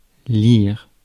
Ääntäminen
IPA: /liʁ/